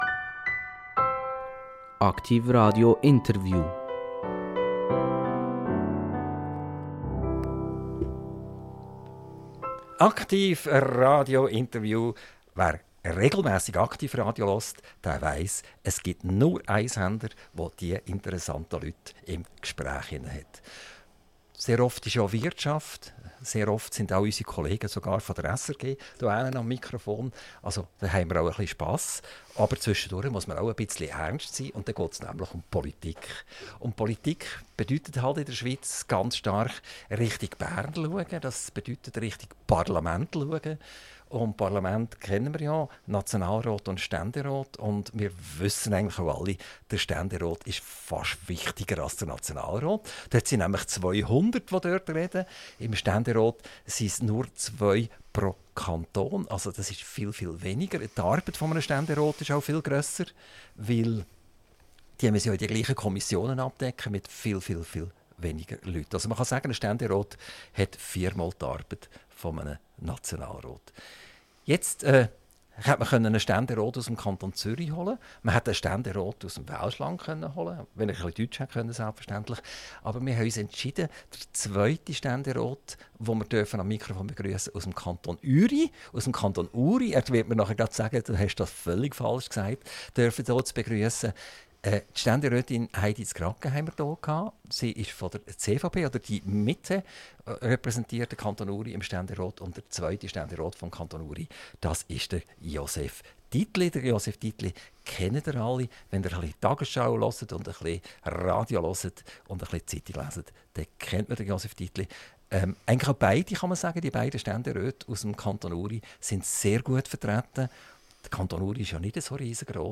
INTERVIEW - Josef Dittli - 23.10.2025 ~ AKTIV RADIO Podcast